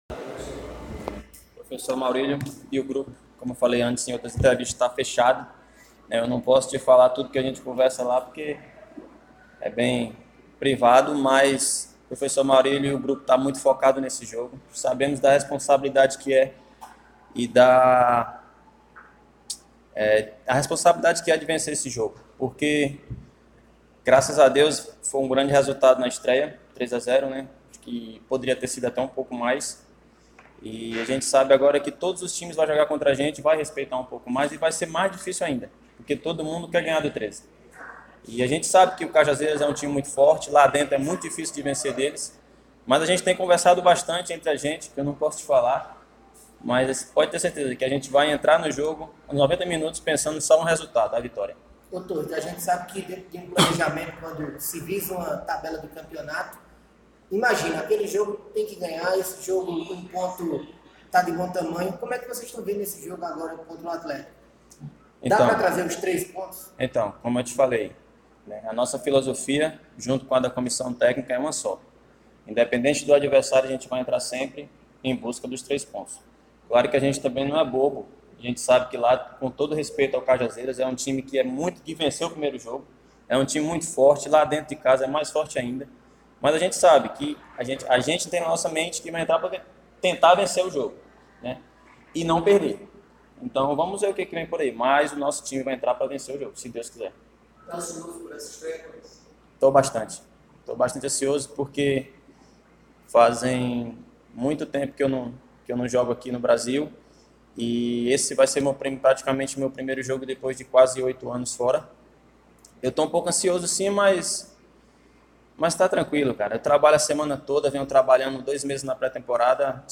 Futebol